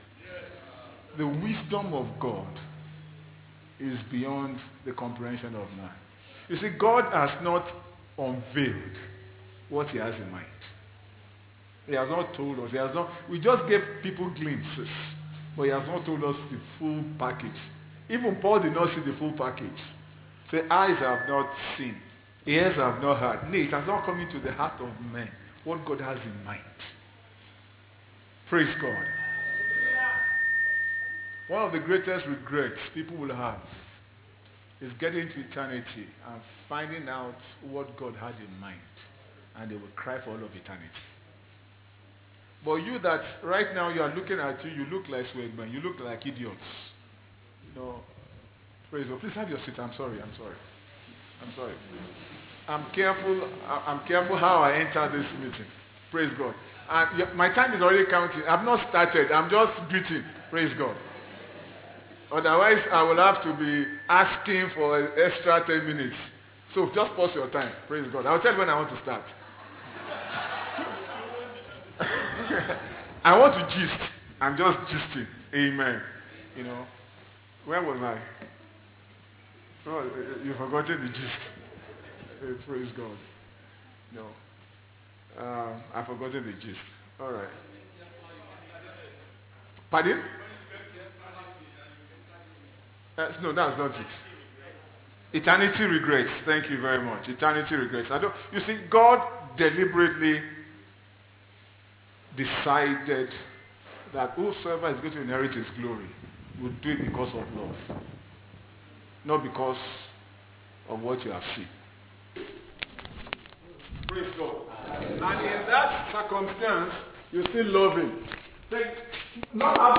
Day 2 Evening Session